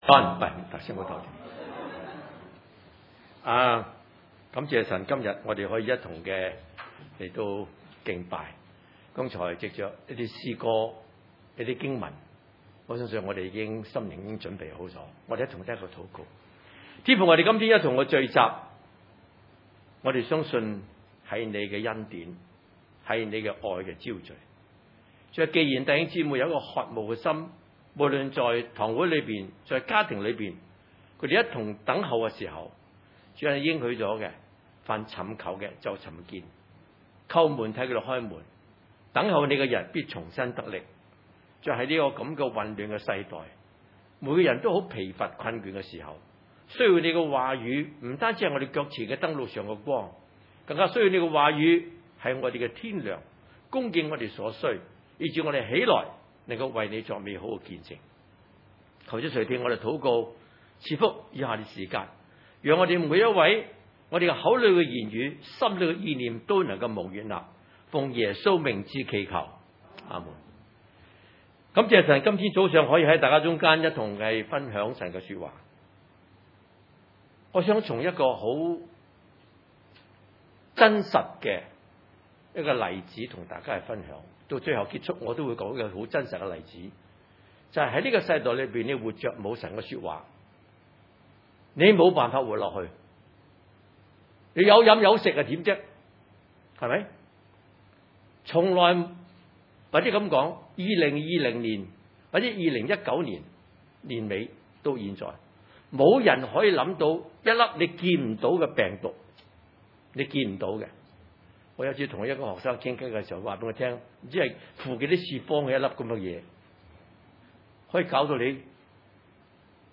來十一6-7 崇拜類別: 主日午堂崇拜 5 神也沒有寬容上古的世代，曾叫洪水臨到那不敬虔的世代，卻保護了傳義道的 挪亞 一家八口。